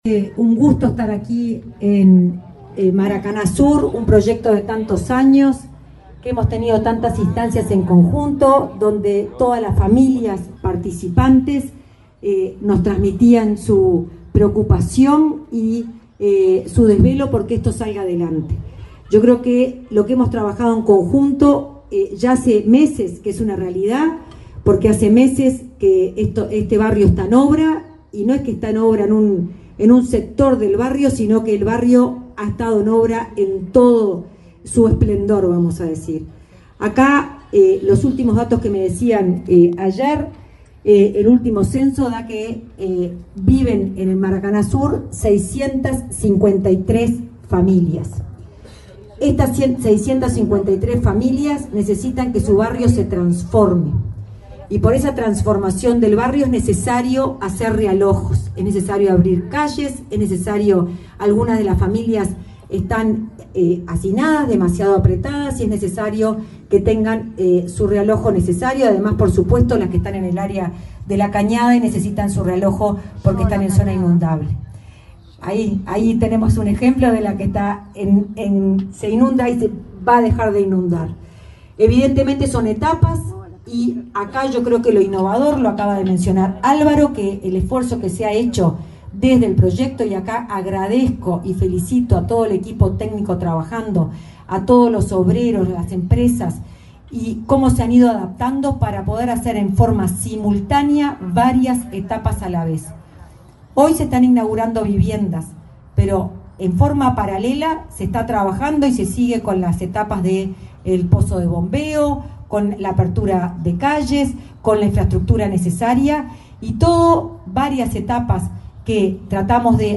Palabras de autoridades del Ministerio de Vivienda
Palabras de autoridades del Ministerio de Vivienda 21/11/2024 Compartir Facebook X Copiar enlace WhatsApp LinkedIn La directora de Integración Social y Urbana del Ministerio de Vivienda, Florencia Arbeleche, y el ministro interino, Tabaré Hackenbruch, participaron en la inauguración de viviendas del plan Avanzar en el barrio Maracaná Sur, en Montevideo.